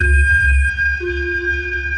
sonarPingWaterFarShuttle2.ogg